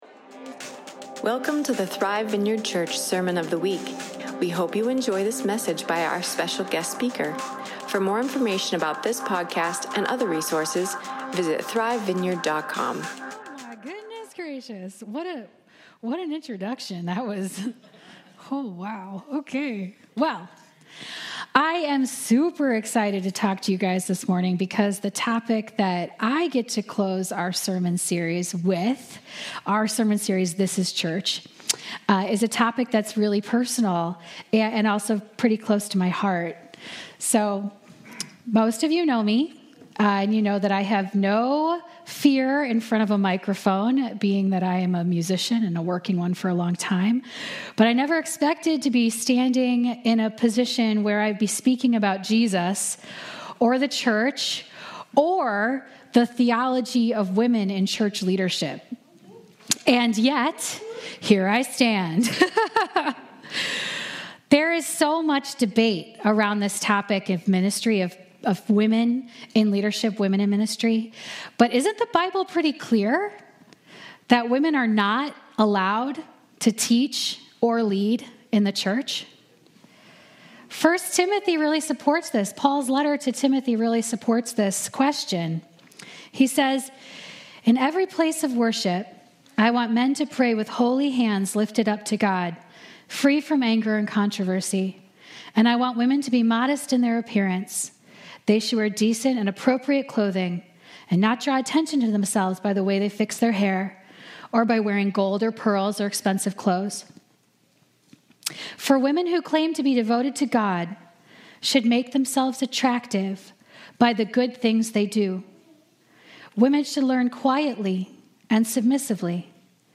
Sunday Service